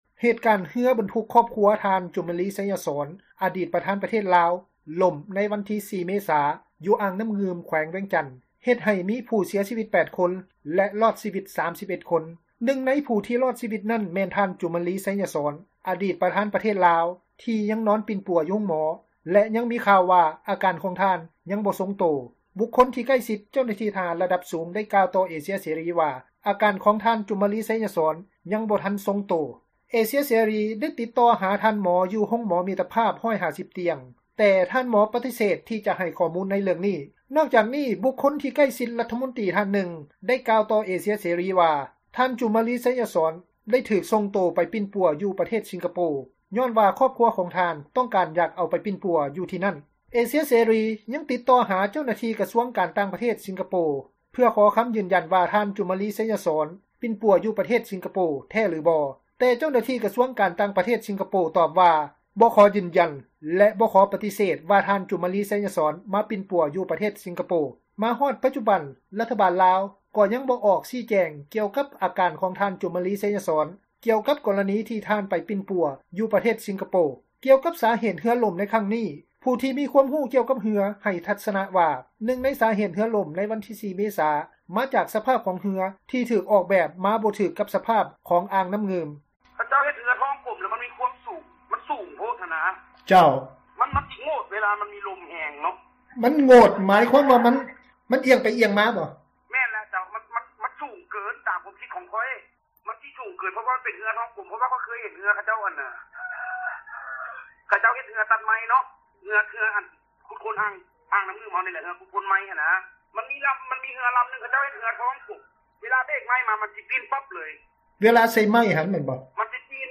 ກ່ຽວກັບສາເຫດຂອງເຮືອຫຼົ້ມໃນຄັ້ງນີ້, ຜູ້ທີ່ມີຄວາມຮູ້ກ່ຽວກັບເຮືອ ໃຫ້ທັສນະວ່າ ນຶ່ງໃນສາເຫດຂອງເຮືອຫຼົ້ມ ໃນວັນທີ 4 ເມສາ ນີ້ ມາຈາກສະພາບຂອງເຮືອ ທີ່ຖືກອອກແບບບໍ່ຖືກ ກັບສະພາບຂອງອ່າງນ້ຳງື່ມ. ຊາວບ້ານຜູ້ທີ່ມີປະສົບການ ໃນການຂັບເຮືອ ໄດ້ໃຫ້ສຳພາດຕໍ່ເອເຊັຽເສຣີວ່າ ເຮືອທີ່ຫຼົ້ມນັ້ນ ຈະເປັນເຮືອທ້ອງກົມ ຍ້ອນວ່າເວລາ ລົມພັດ ເຮືອຈະຫຼົ້ມໄດ້ງ່າຍ.